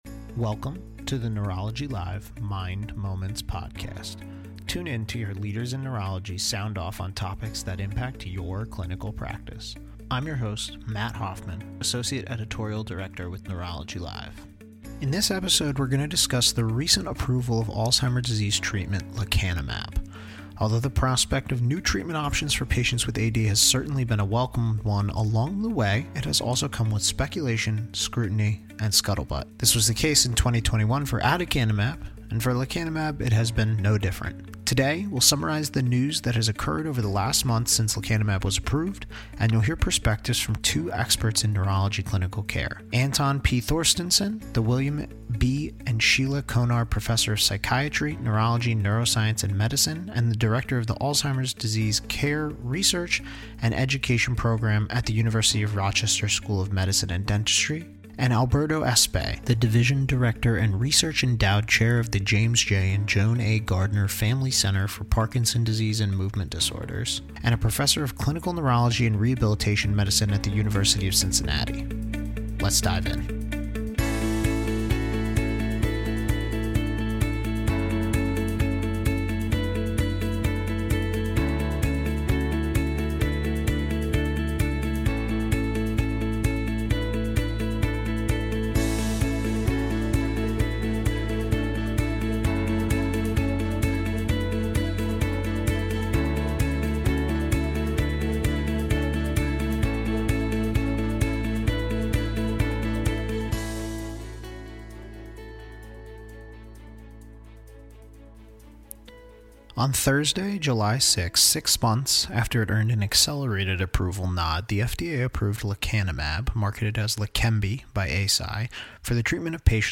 This episode features an in-depth dive into the recent approval of lecanemab (Leqembi; Eisai) for Alzheimer disease and the ongoing debate about its potential benefit, the amyloid-targeting class of medicines, and the communication of data. Featured in this episode is commentary from 2 experts in neurology clinical care